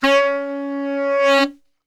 C#2 SAXSWL.wav